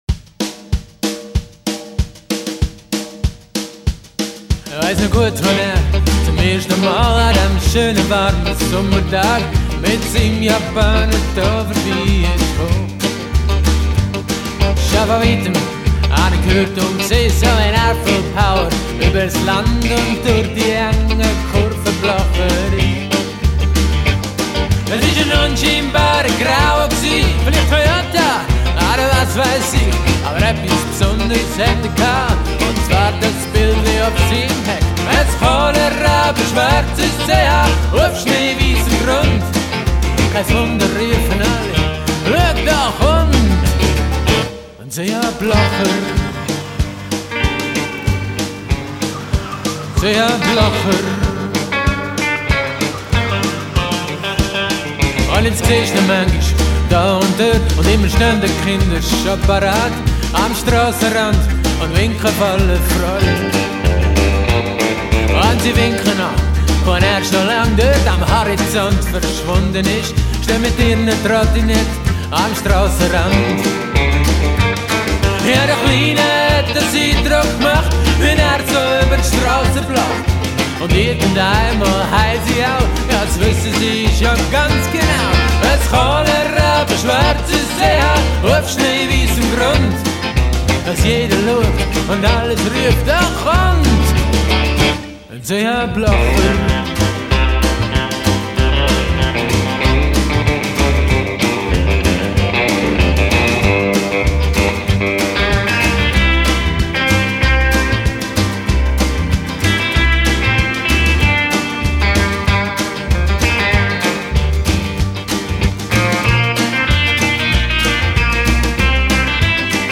Schlagzeug
Bass
E-Gitarren, Banjo
Hammond A 100
Gesang, akustische Gitarre